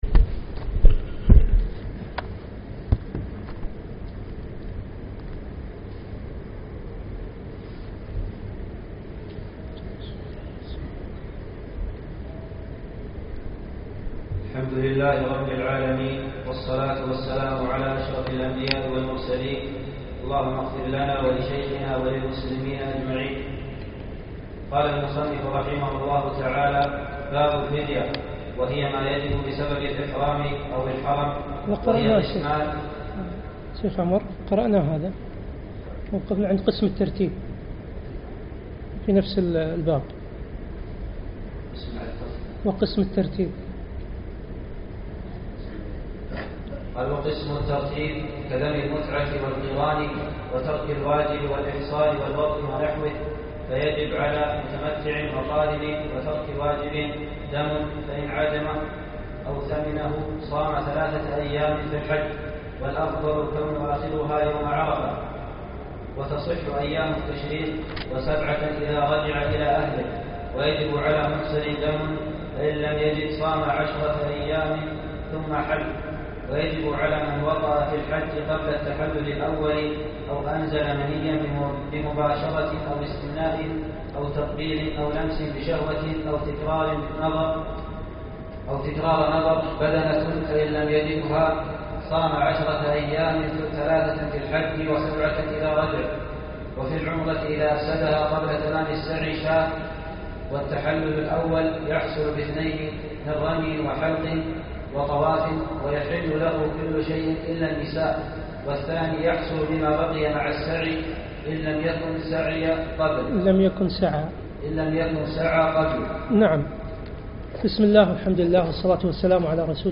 يوم الأثنين 23 ذو القعدة 1436 الموافق 7 9 2015 مسجد سالم العلي الفحيحيل
الدرس الثاني